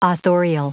Prononciation du mot authorial en anglais (fichier audio)
Prononciation du mot : authorial